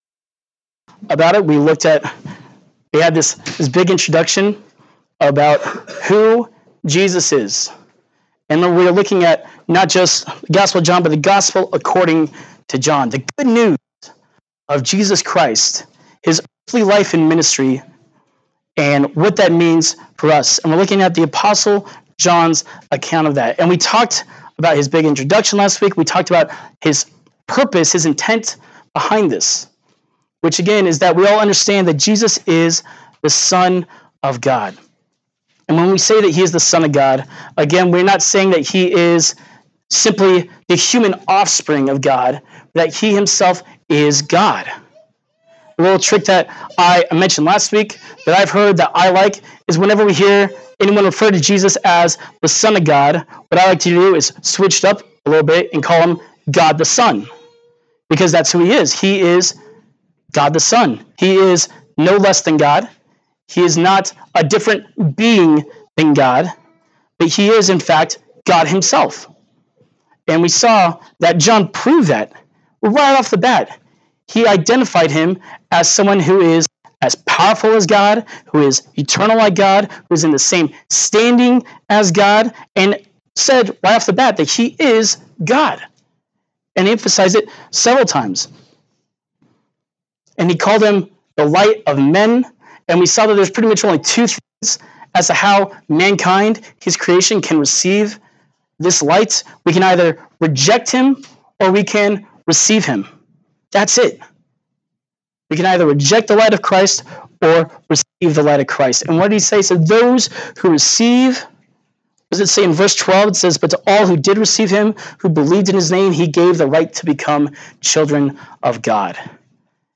John 1:19-34 Service Type: Sunday Morning Worship « John 1:1-18